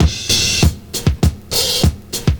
100CYMB04.wav